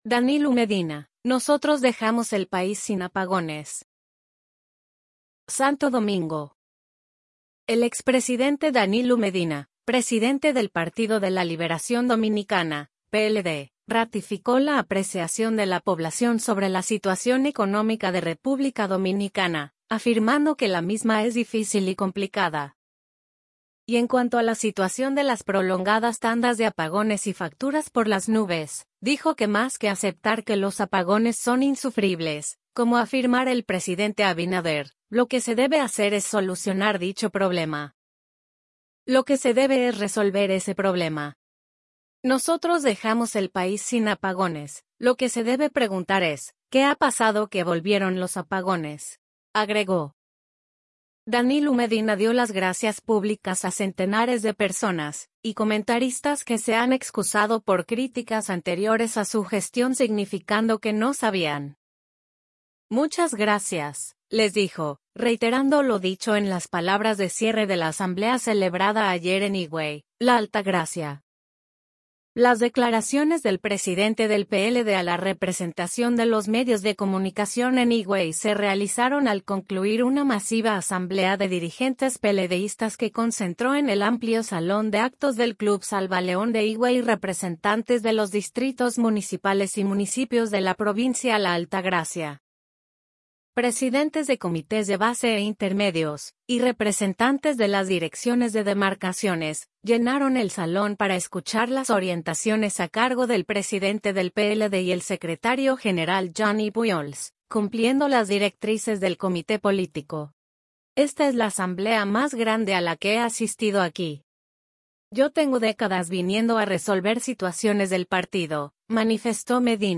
Las declaraciones del presidente del PLD a la representación de los medios de comunicación en Higüey se realizaron al concluir una masiva asamblea de dirigentes peledeístas que concentró en el amplio salón de actos del Club Salvaleón de Higüey representantes de los distritos municipales y municipios de la provincia La Altagracia.